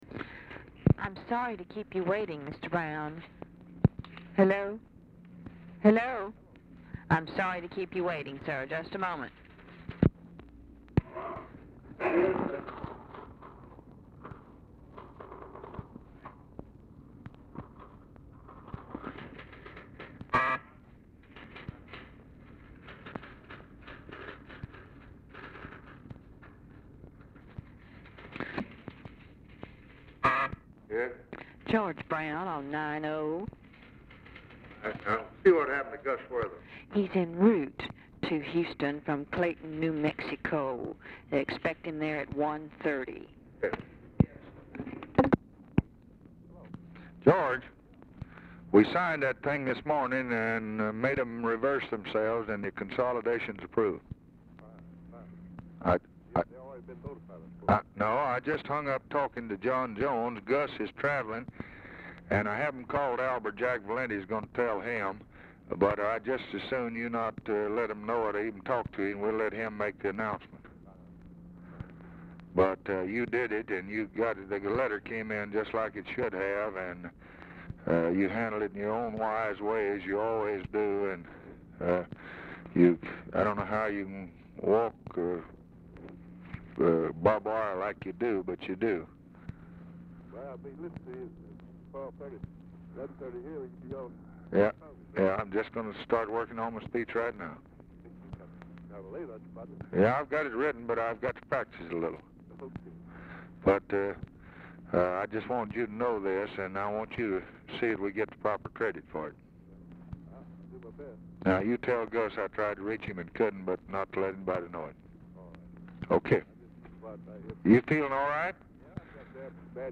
Telephone conversation
Dictation belt
Oval Office or unknown location